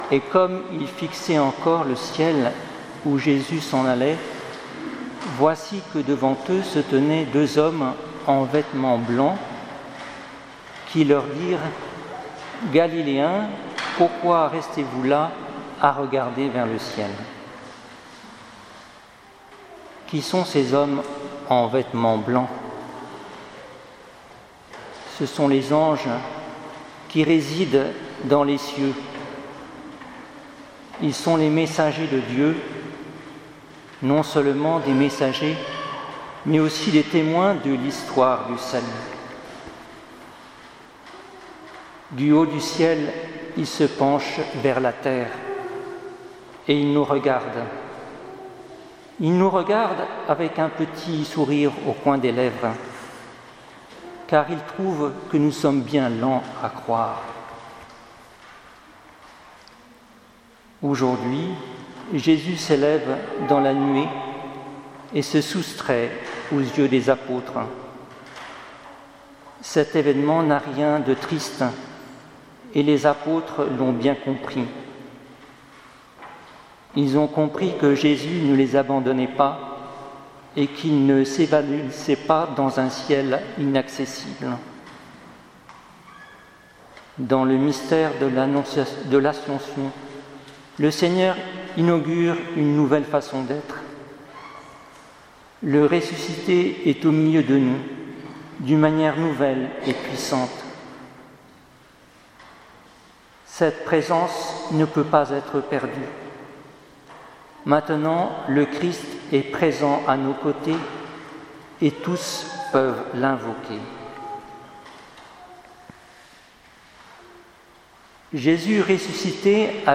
Homélie de l’Ascension 2018